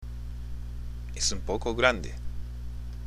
（エス　ウン　ポコ　グランデ）